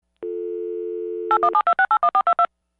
На этой странице собраны звуки набора номера в телефоне — от винтажных импульсных гудков до современных тональных сигналов.
Звук повторного набора номера в режиме redial